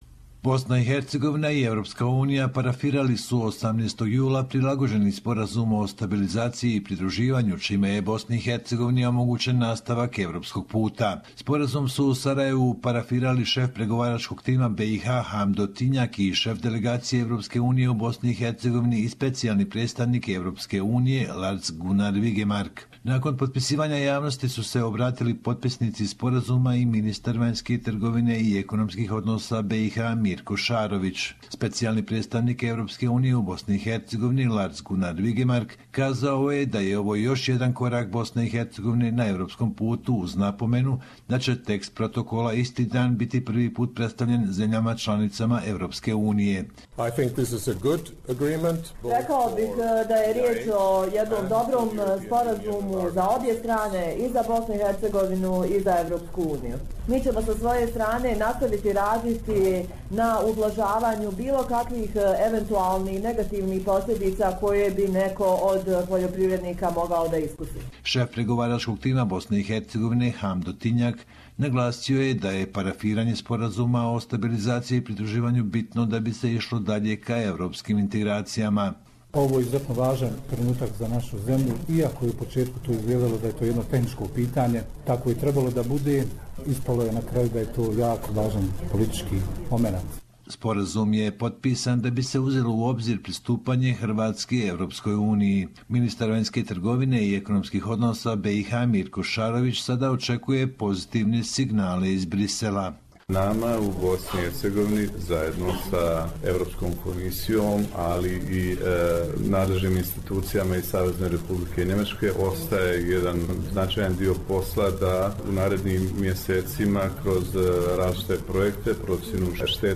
Report from Bosnia and Herzegovina